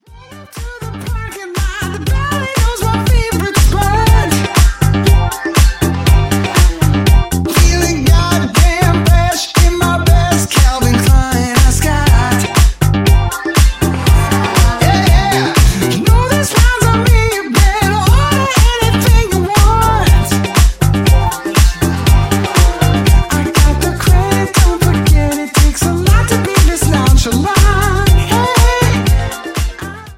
ジャンル(スタイル) NU DISCO / HOUSE